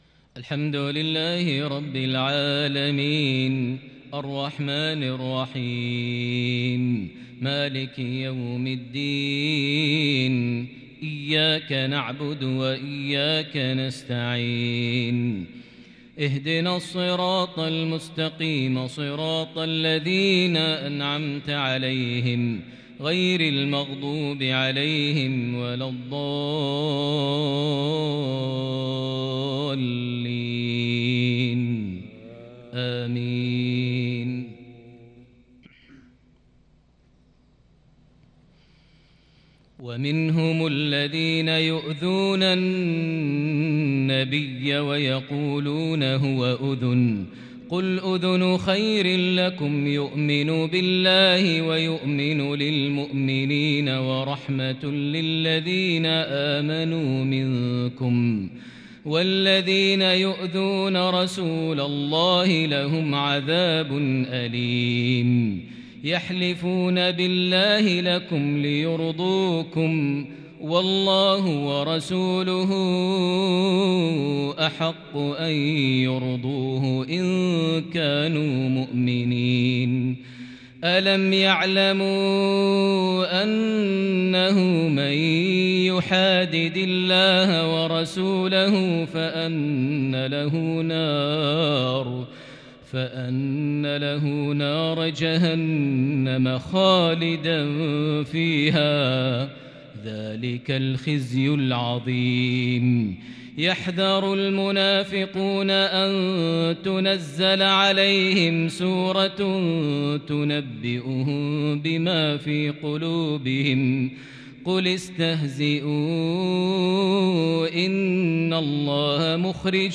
(نسوا الله فنســيهم ) عشائية متألقة بالكرد من سورة التوبة (61-72) | الجمعة 10 جمادى الأول 1442هـ > 1442 هـ > الفروض - تلاوات ماهر المعيقلي